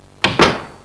Closing Door () Sound Effect
Download a high-quality closing door () sound effect.
closing-door.mp3